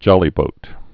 (jŏlē-bōt)